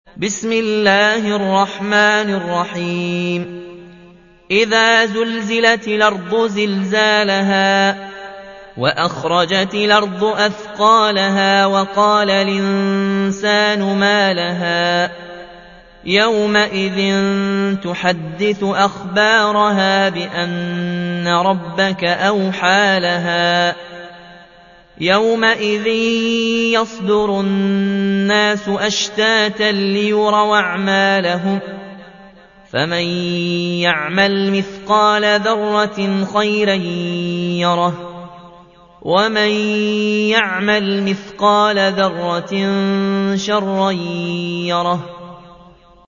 تحميل : 99. سورة الزلزلة / القارئ ياسين الجزائري / القرآن الكريم / موقع يا حسين